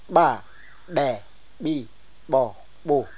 Nïpóde-speaking people associate their identity with their speech which features distinct glottalised sounds, a particular pronunciation for some words and specific words not found in other Uitoto dialects.
The sample used for this phonetic study was collected in the field during my ethnographic research undertaken in the Middle Caquetá region of the Colombian Amazon between 1994 and 1996.